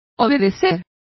Complete with pronunciation of the translation of obeyed.